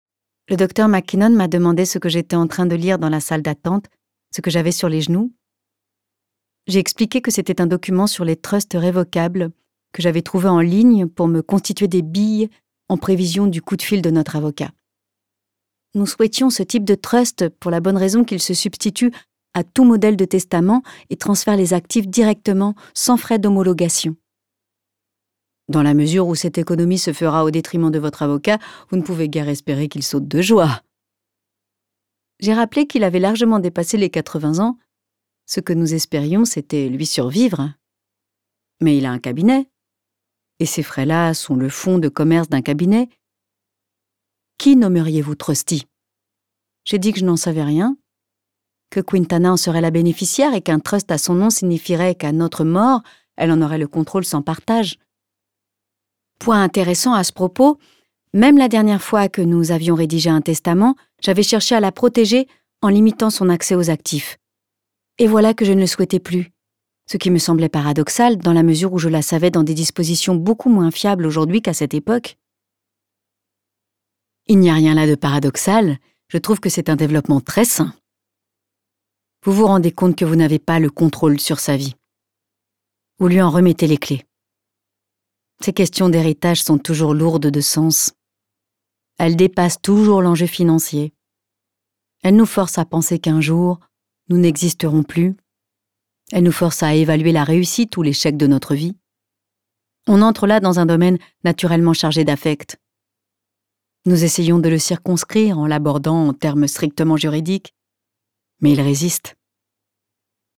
« Notes à John » de Joan Didion, lu par Isabelle Carré - Studio nova